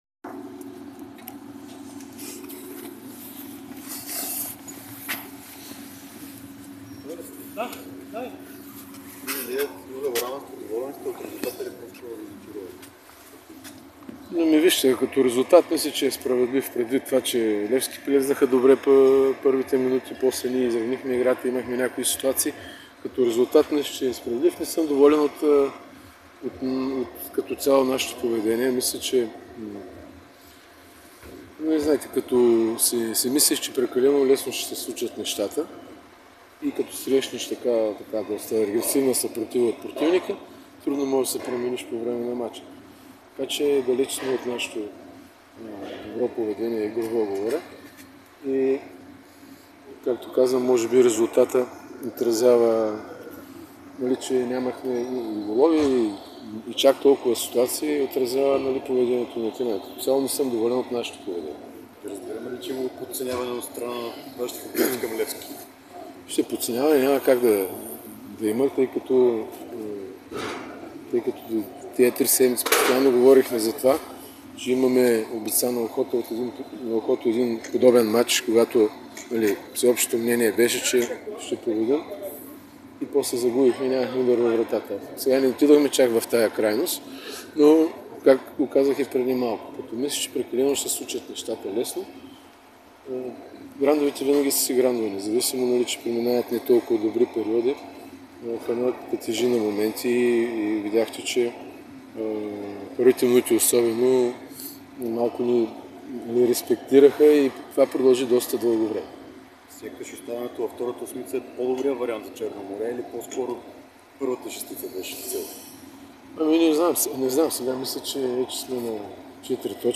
Треньорът на Черно море Илиан Илиев сподели след нулевото равенство с Левски на "Тича", че е доволен от равенството, но не и от игровото поведение на неговия тим.